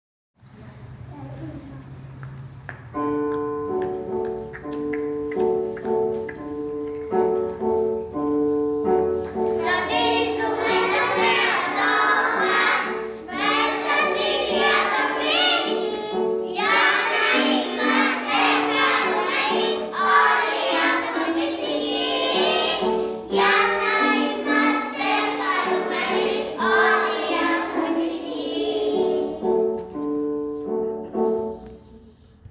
Συμμετοχή του νηπιαγωγείου στο διαγωνισμό μαθητικού ραδιοφώνου "Κάντο ν'ακουστεί"
μ' ένα συνεργατικό τραγούδι που έχει θέμα τη διατροφή.